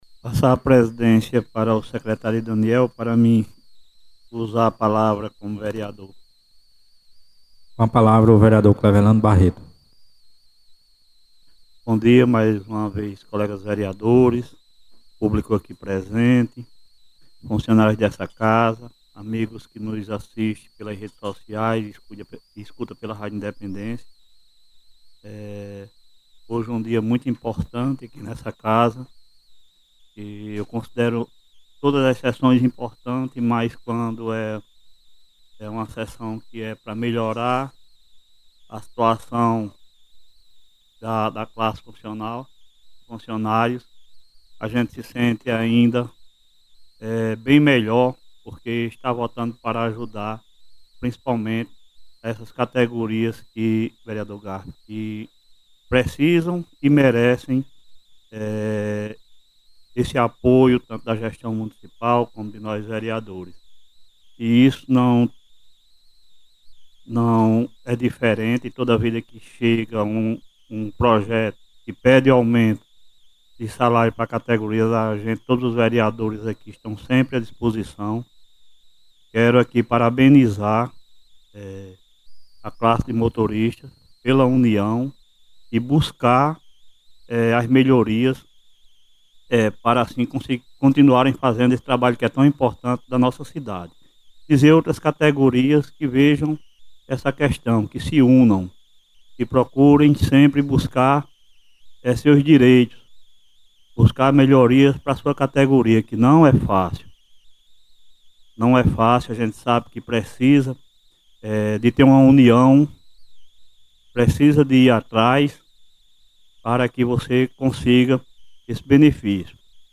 Em Sessão Extraordinária na manhã desta quinta-feira, 11 de dezembro, marcada por decisões importantes para o funcionalismo público, o presidente da Câmara Municipal de Catolé do Rocha, vereador Cleverlando Barreto, fez um pronunciamento firme, sensível e centrado na valorização dos servidores municipais, especialmente da categoria dos motoristas, que lotou o plenário da Casa.